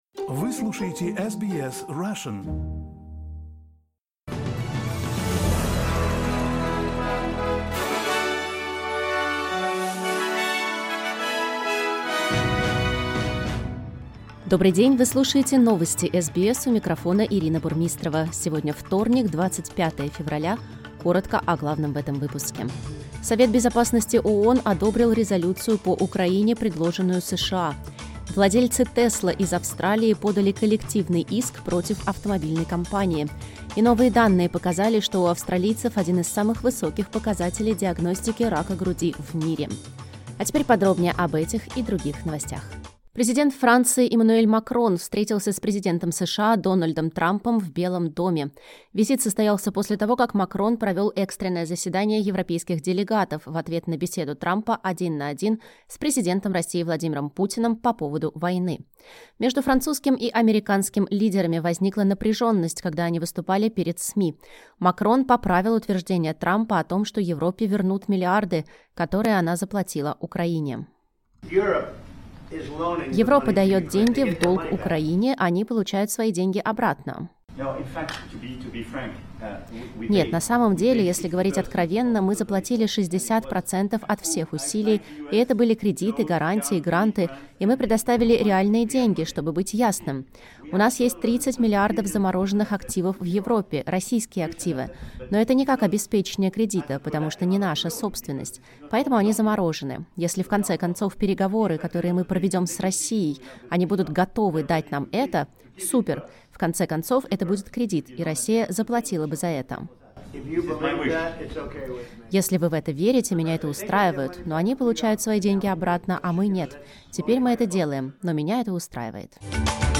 Новости SBS на русском языке — 25.02.2025